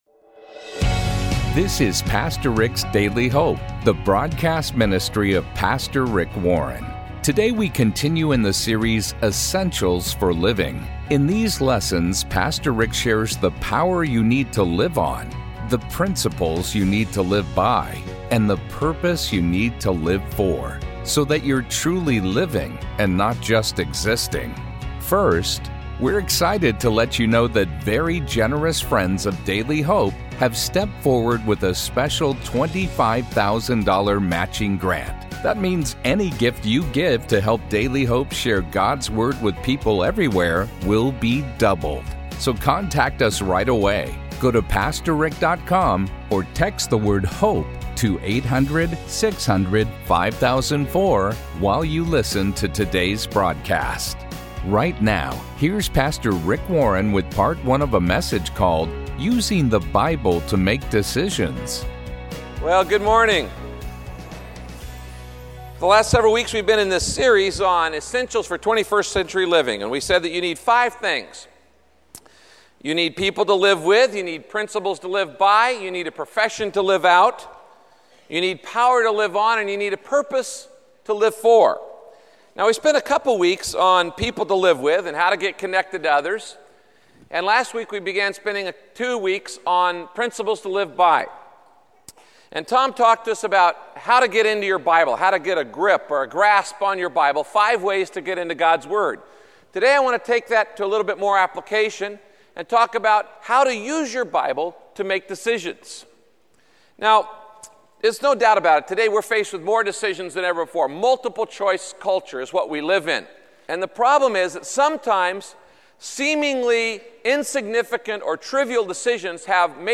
My Sentiment & Notes Using the Bible to Make Decisions - Part 1 Podcast: Pastor Rick's Daily Hope Published On: Wed Aug 23 2023 Description: What will be the ultimate authority in your life? In this broadcast, Pastor Rick teaches why it’s essential to make your decisions based on God’s Word and not on what other people say.